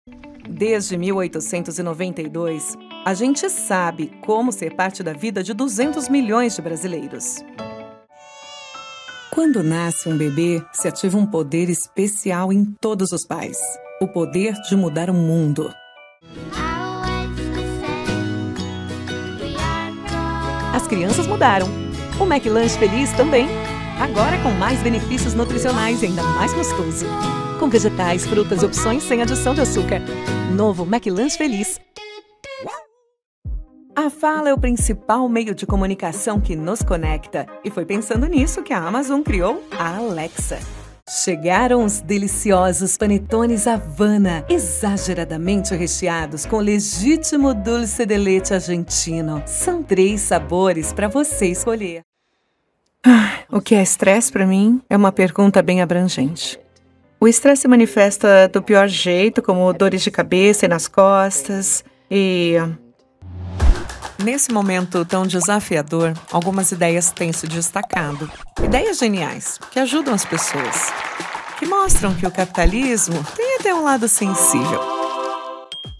Female
Teenager (13-17), Adult (30-50)
Accents: I have a warm, clear tone but extremely versatile and I can do a great many voices.
Main Demo
All our voice actors have professional broadcast quality recording studios.